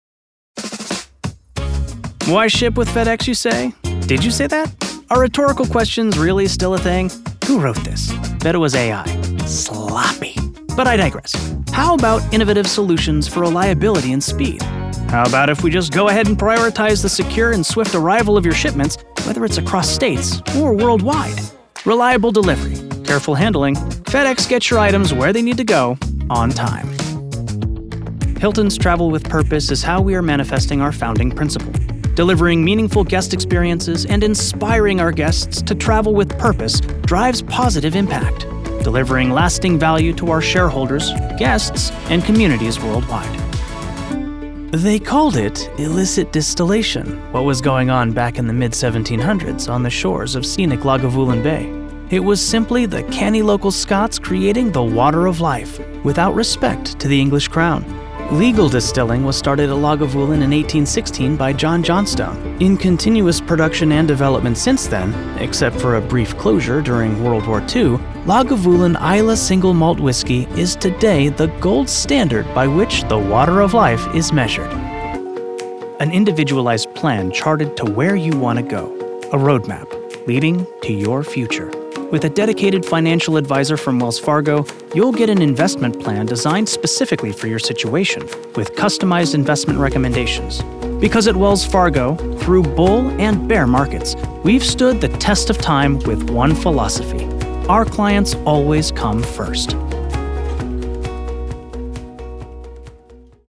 Corporate & Industrial Voice Overs
Adult (30-50) | Yng Adult (18-29)